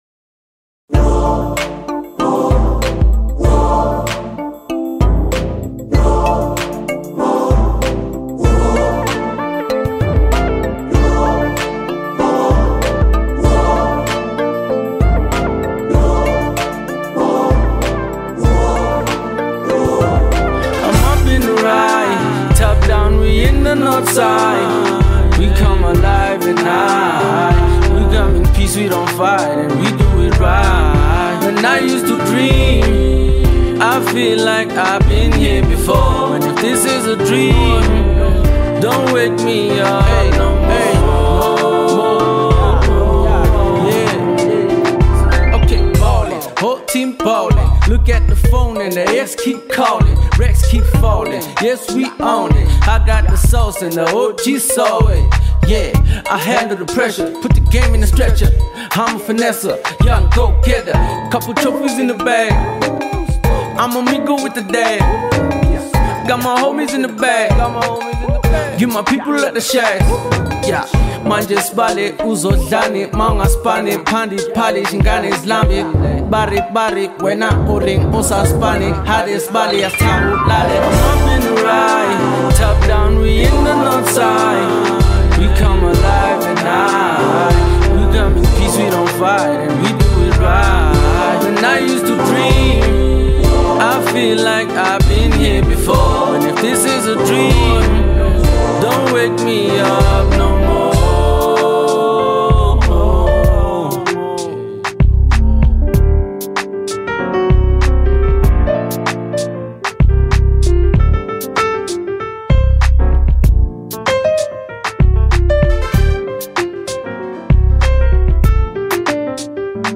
strong vocals, emotional depth